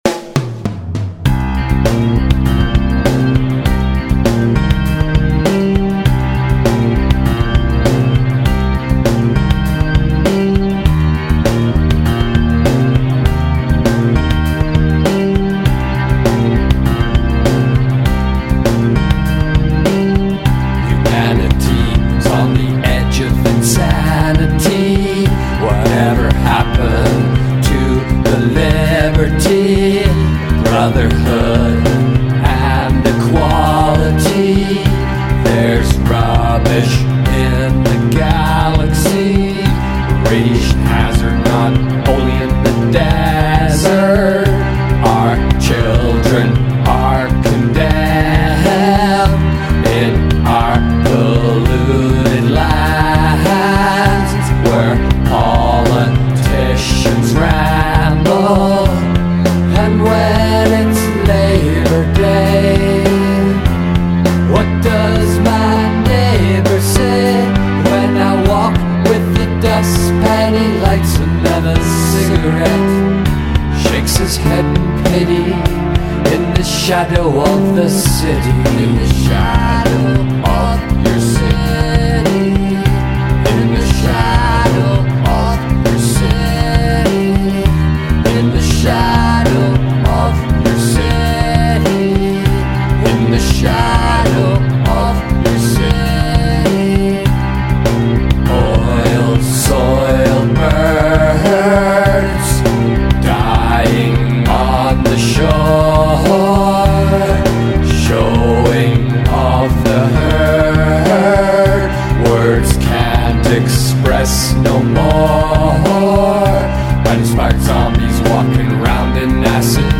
GENRE - Rock - Various Styles